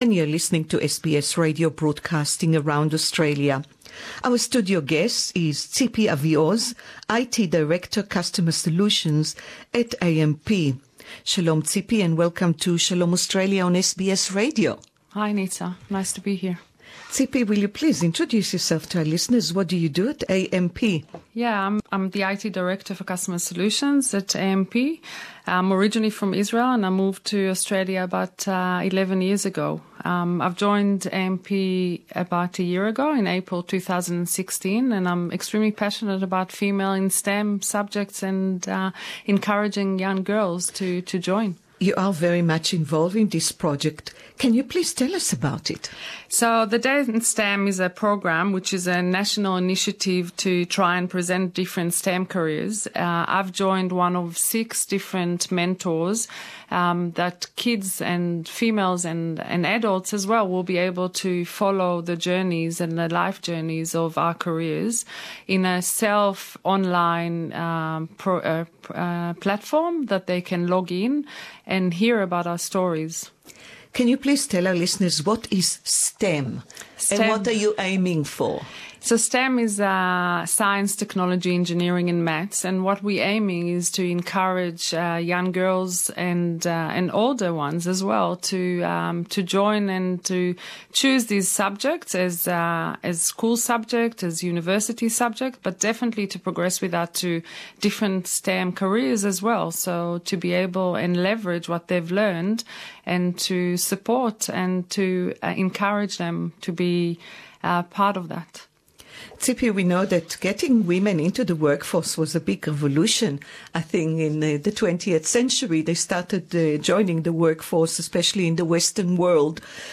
(interview in English)